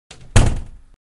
slam.mp3